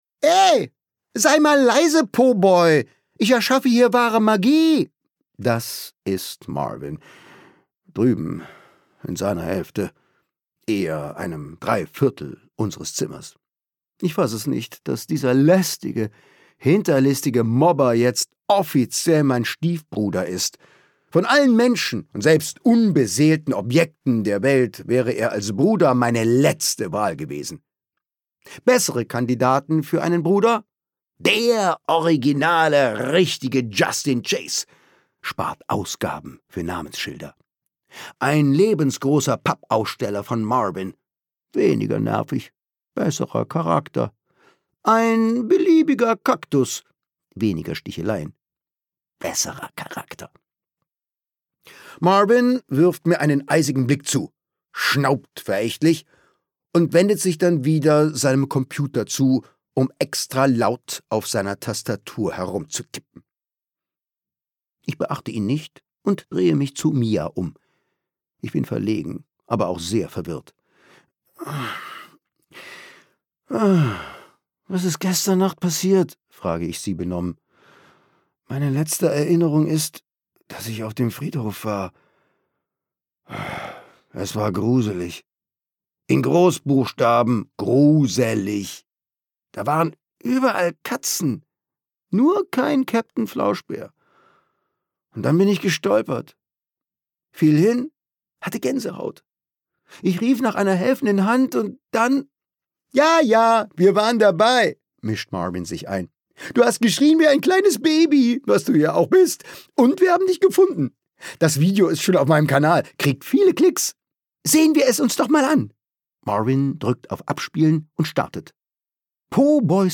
Schule, Peinlichkeiten und ganz viel Humor – Cooles Hörbuch für Kinder ab 10 Jahre
Gekürzt Autorisierte, d.h. von Autor:innen und / oder Verlagen freigegebene, bearbeitete Fassung.